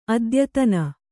♪ adyatana